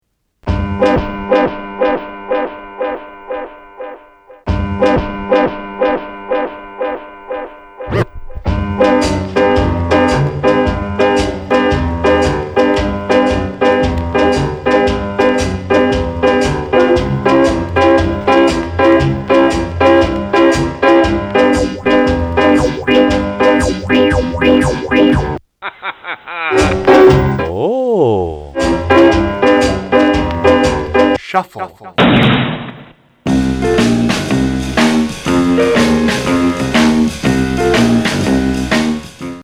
オール・ジャンルの音楽の中に潜む、ジャンピン・ジャイブな跳ねるリズム
『シャッフル』感を抽出し、時代をザックリ跨いでのミックス！！
ジャイビンなイントロからアーリー・スカ、ロックン・ロールにレゲー＆ダブ、
そこからググっと絞ってエレクトロ・ビート〜ディスコ・ダブ、コールド・カットも
絡んだN.W.気分なアフロ・ビート、パーカッシブなエキゾ。